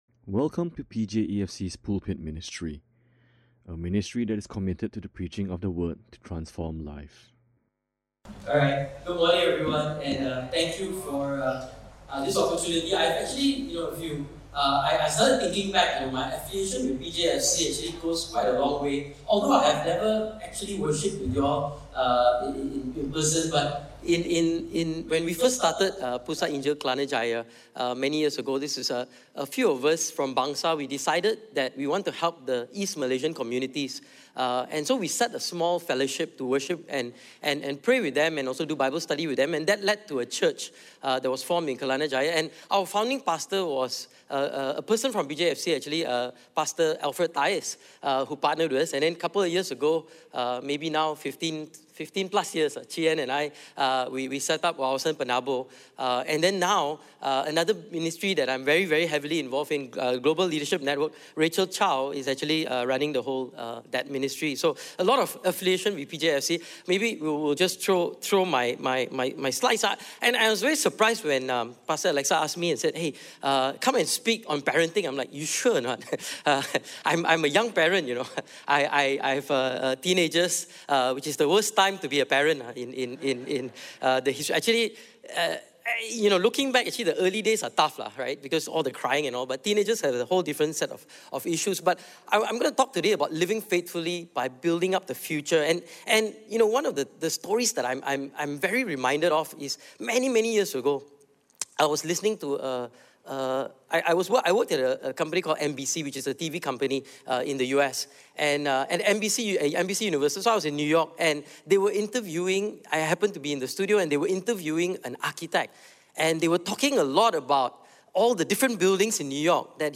Listen to Sermon Only
In conjunction with Parent’s Day, this is a stand-alone sermon by a guest speaker.